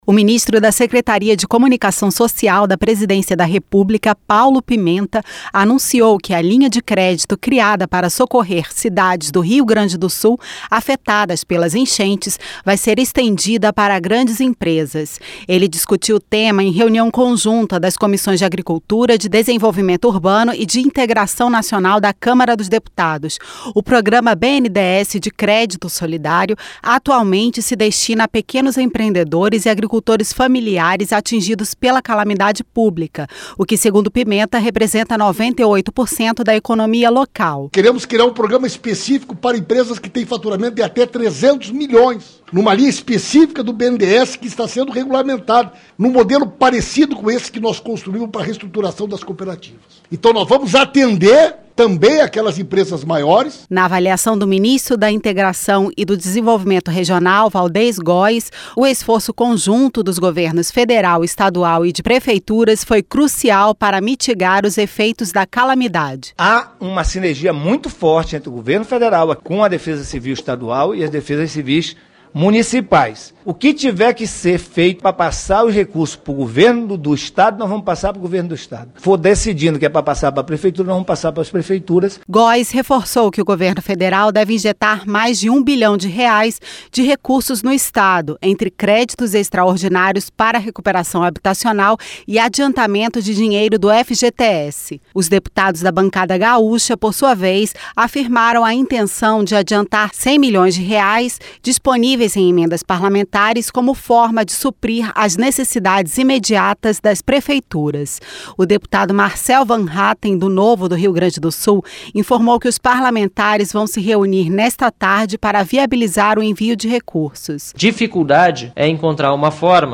• Áudio da matéria